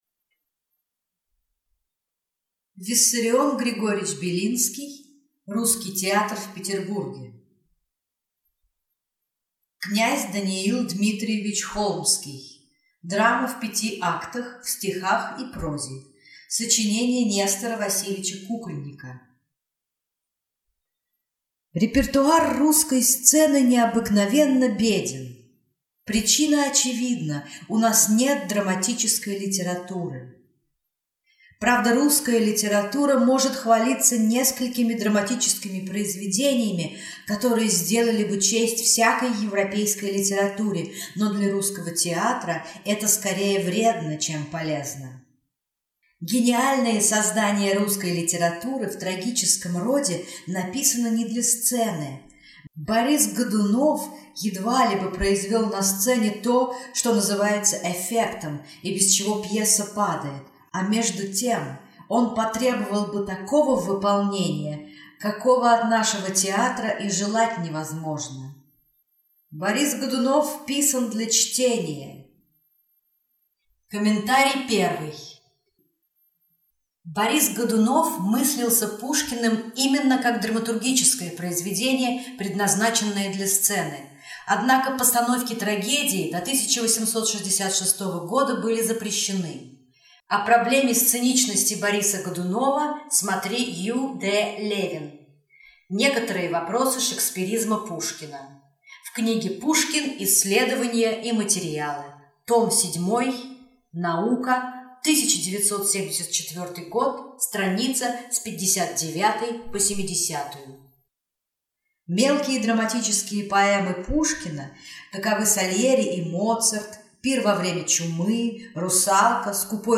Аудиокнига Русский театр в Петербурге | Библиотека аудиокниг